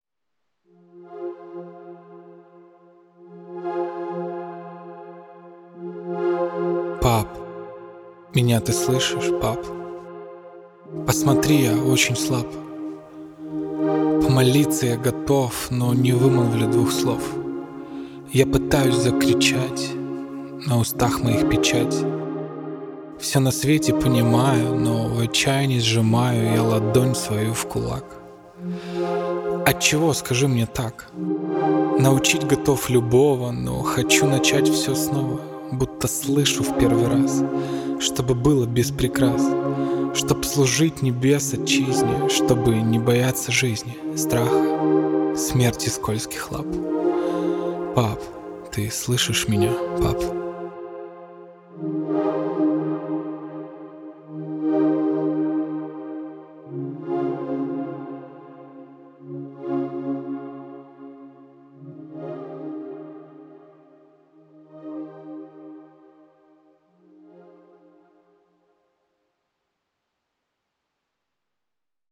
1187 просмотров 1120 прослушиваний 93 скачивания BPM: 138